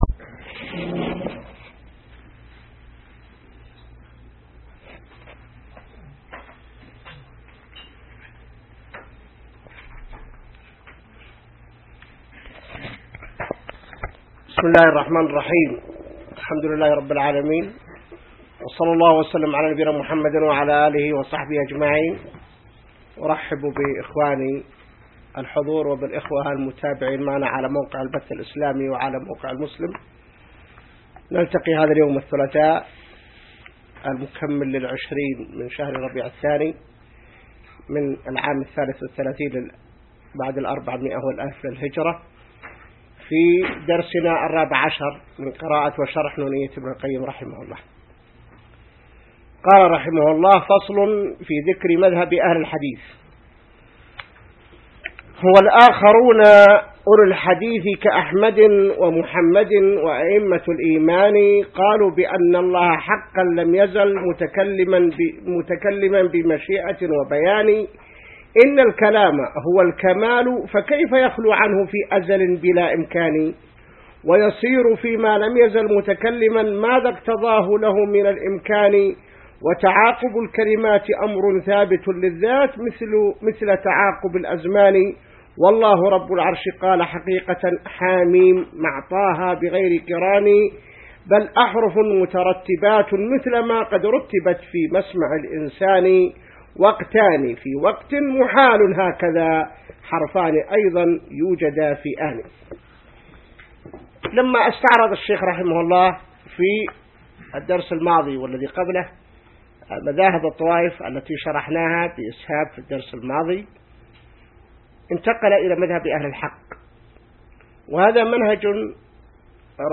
الدرس 14 من شرح نونية ابن القيم | موقع المسلم